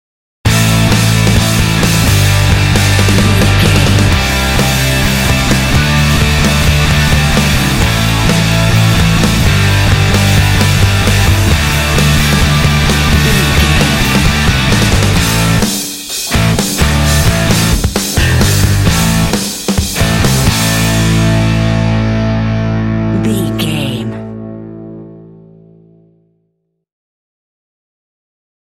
Epic / Action
Aeolian/Minor
powerful
energetic
heavy
electric guitar
drums
bass guitar
heavy metal
classic rock